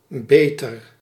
English: Dutch pronunciation of "beter"